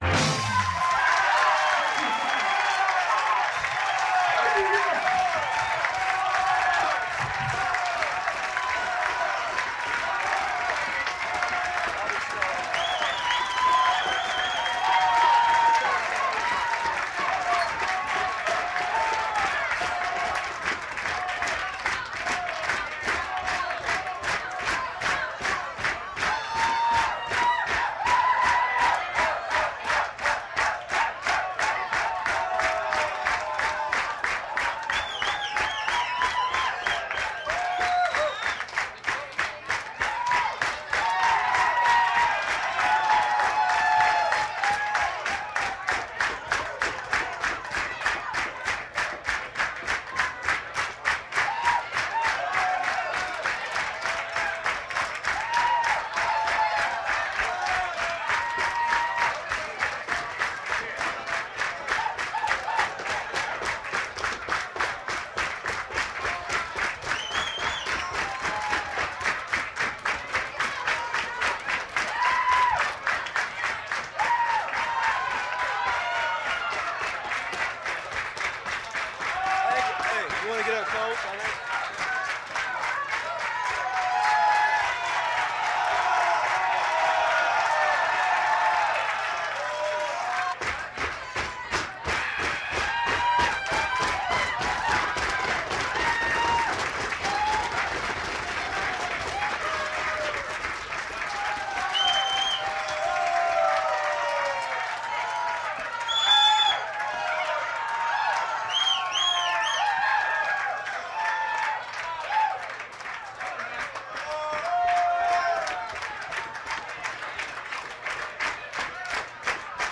Звуки ночного клуба
Шум ночного клуба: конкурсы, аплодисменты и веселье